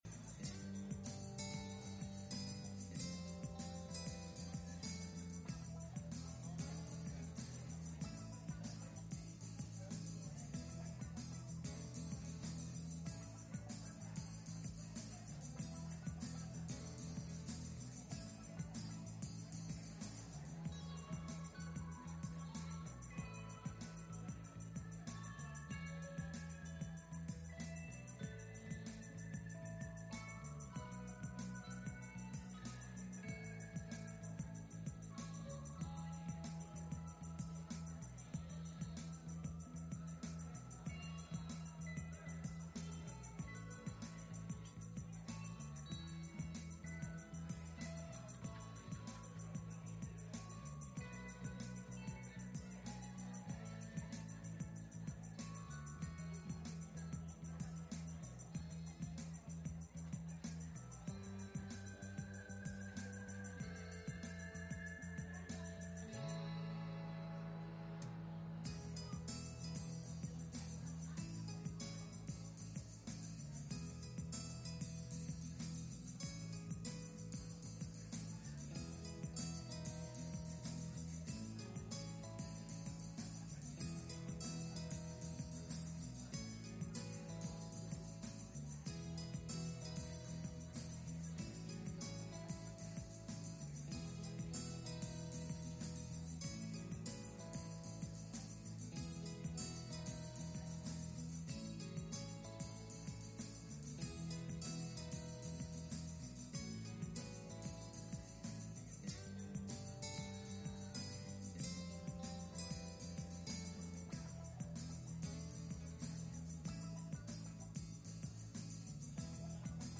Passage: Psalm 119:32 Service Type: Sunday Morning « Defending Your Faith Pt 5